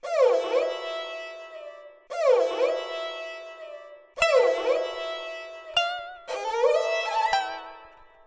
平稳的振动全场合唱团 1 116
标签： 116 bpm Orchestral Loops Strings Loops 1.39 MB wav Key : Unknown FL Studio
声道立体声